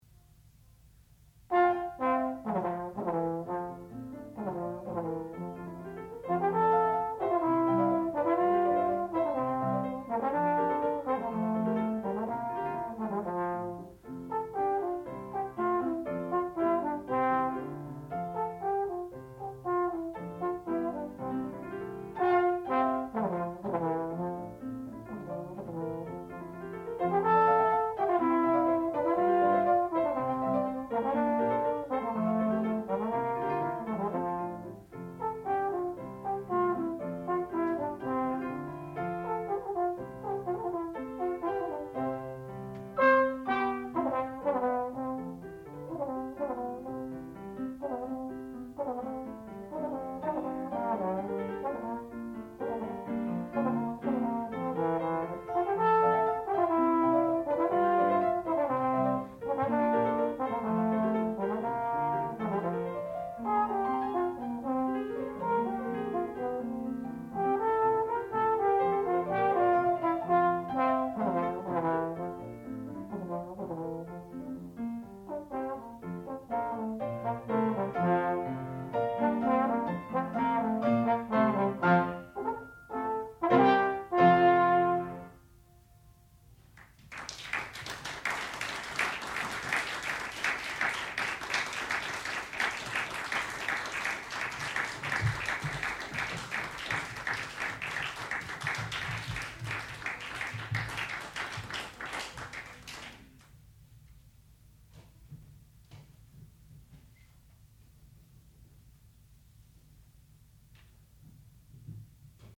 sound recording-musical
classical music
trombone
piano
Qualifying Recital